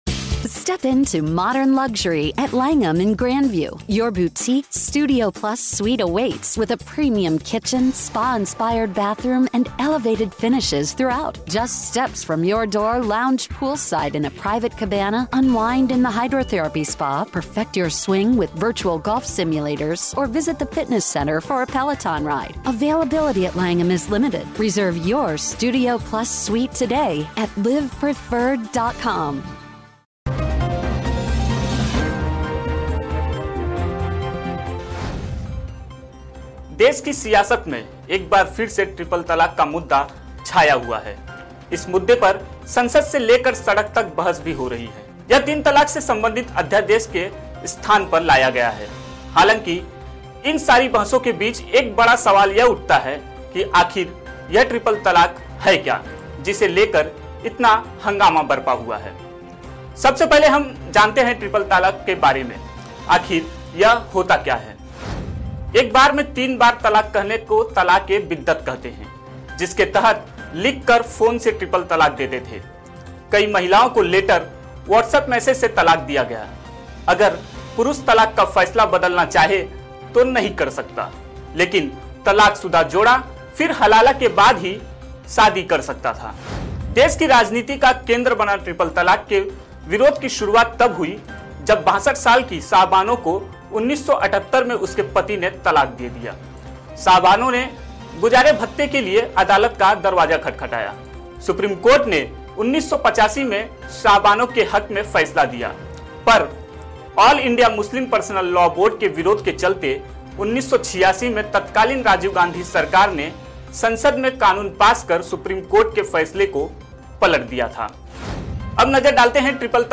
न्यूज़ रिपोर्ट - News Report Hindi / क्या है तीन तलाक और कहां से शुरू हुआ इसका विरोध !